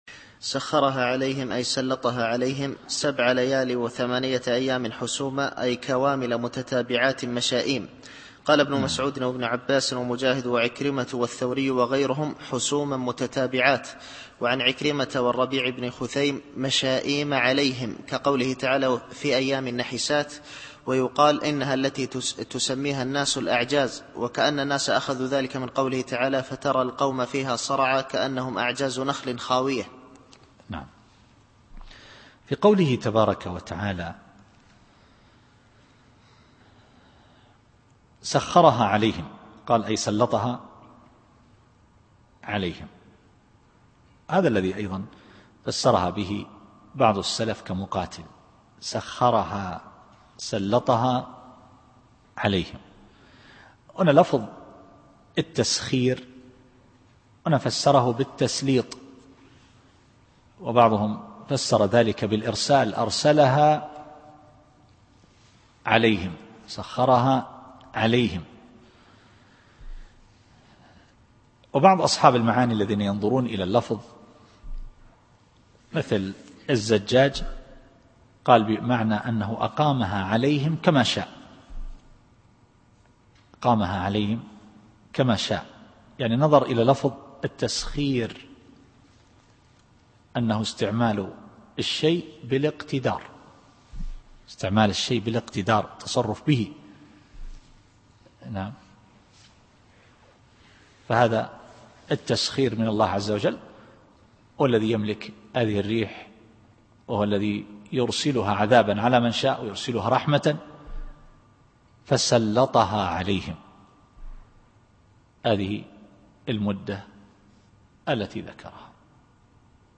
التفسير الصوتي [الحاقة / 7]